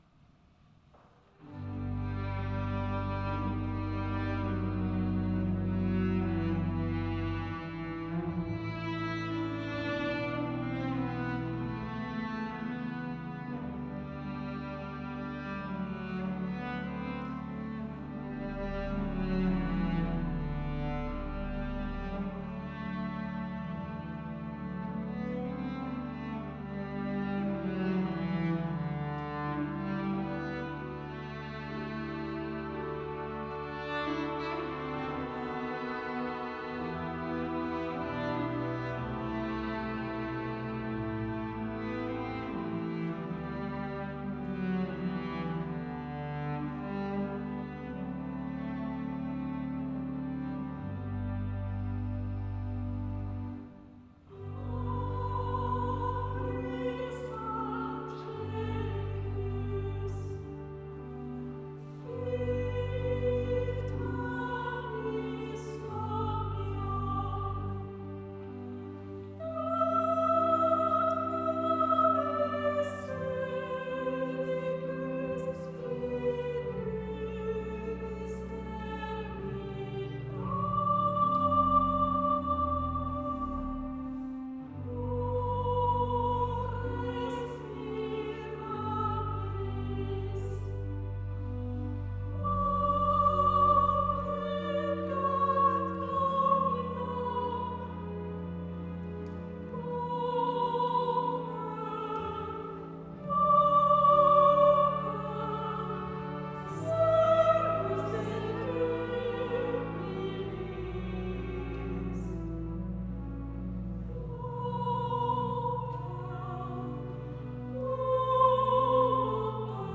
Panis angelicus de César Franck. Prononciation gallicane du latin par respect de la tradition à l'époque de la création de l'oeuvre.